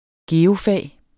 Udtale [ ˈgeːo- ]